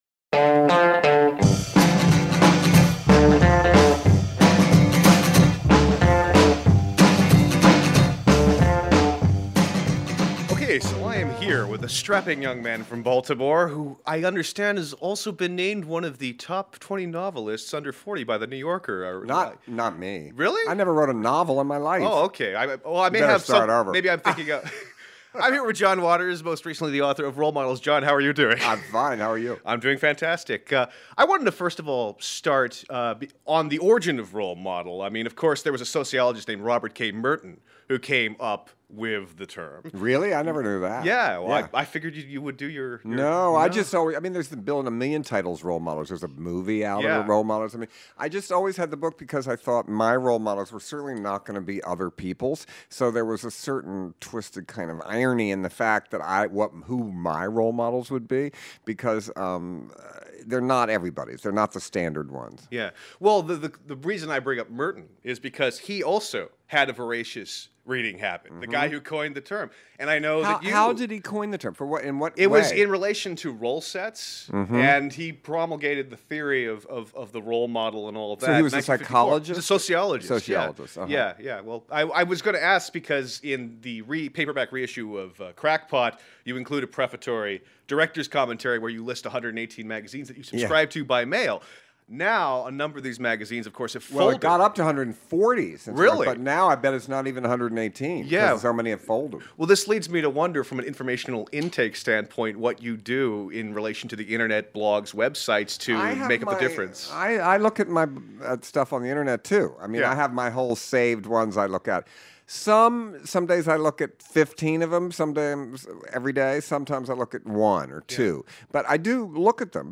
A podcast interview with John Waters